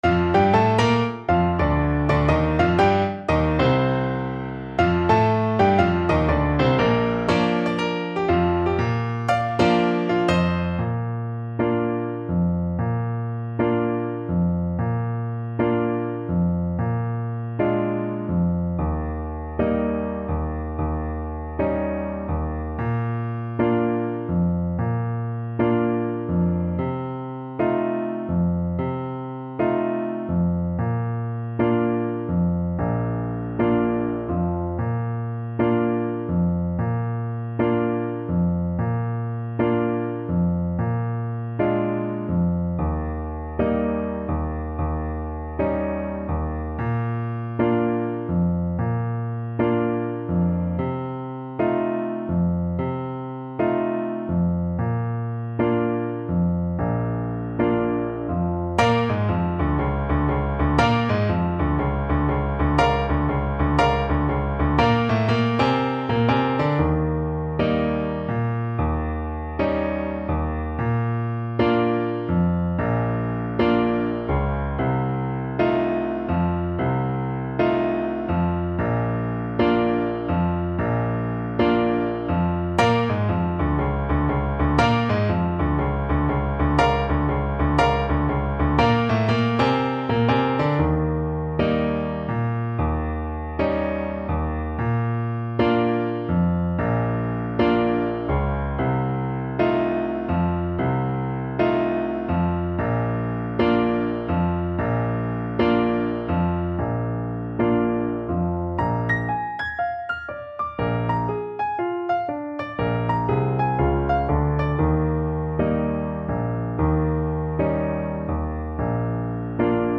Moderato = 120
Jazz (View more Jazz Trumpet Music)